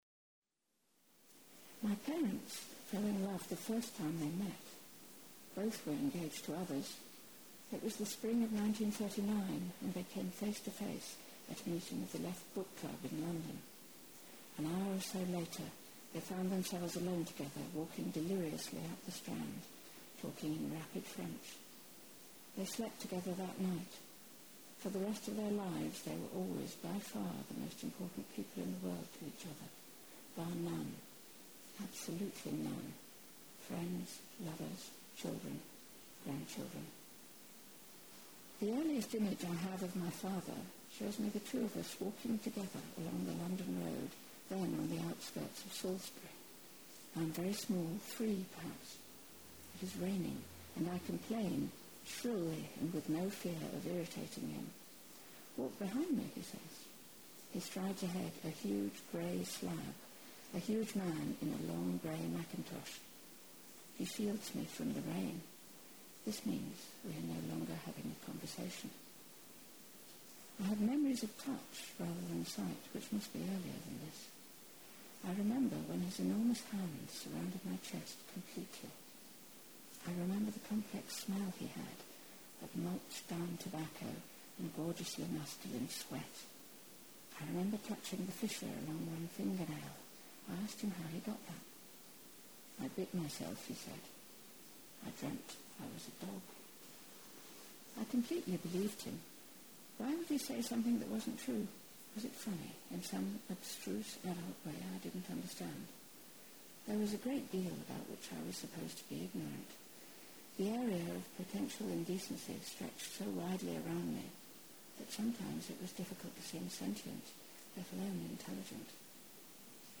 reading extracts from the book
Reading 2 “My parents fell in love the first time they met.